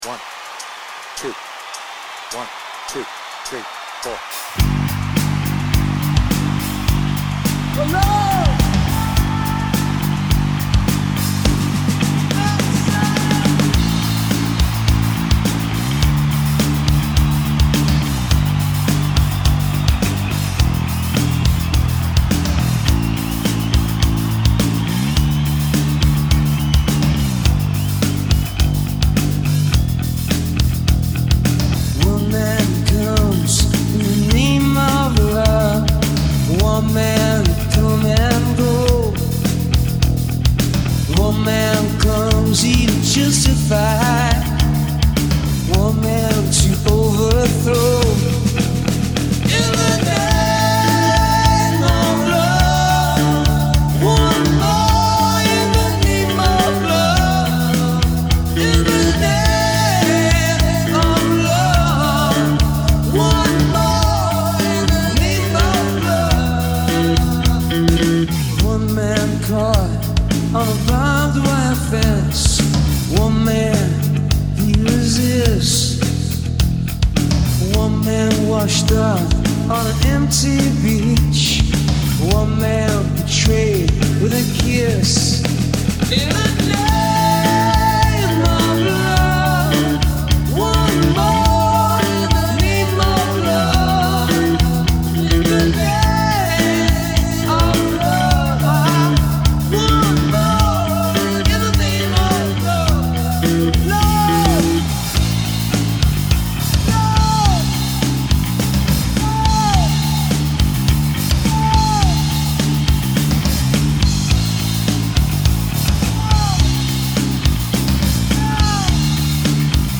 BPM : 102
Tuning : Eb
With Vocals